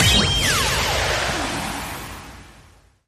Feature_Buy_Start_Sound.mp3